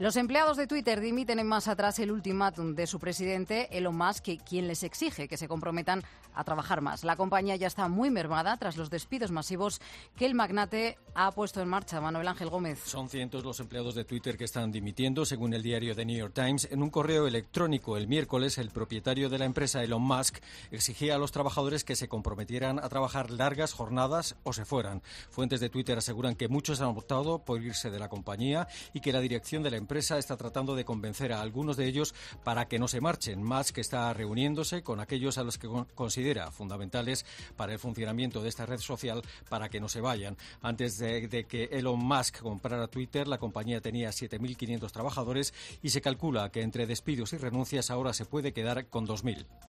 Elon Musk trata de convencer a los trabajadores "fundamentales" para que no se vayan. Crónica